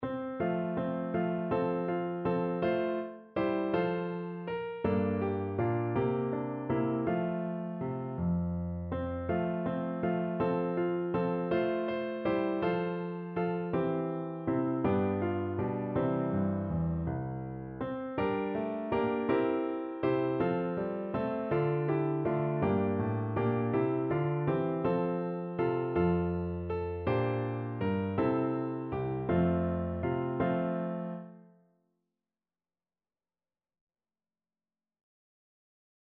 Piano version
No parts available for this pieces as it is for solo piano.
3/4 (View more 3/4 Music)
One in a bar .=c.54